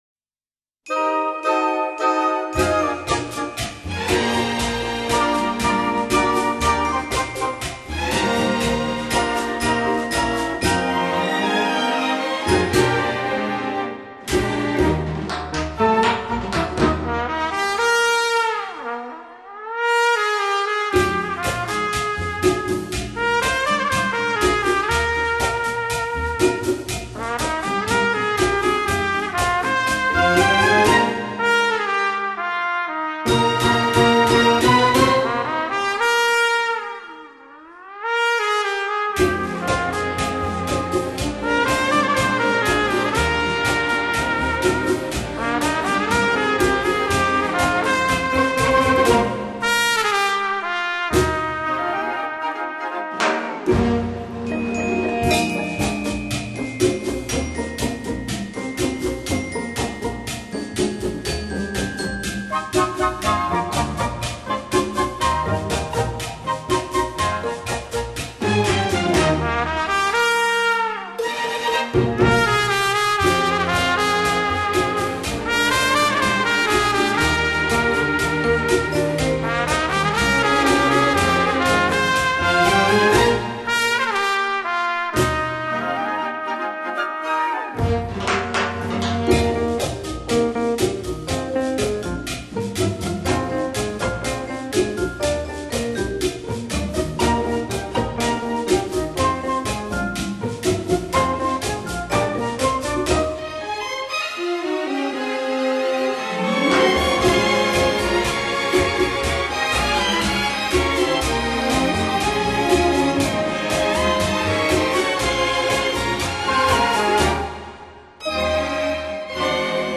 演奏以轻音乐和舞曲为主。